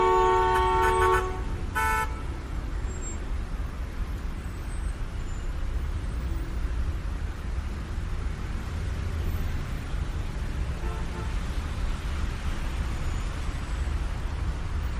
Truck Driving
Truck Driving is a free ambient sound effect available for download in MP3 format.
356_truck_driving.mp3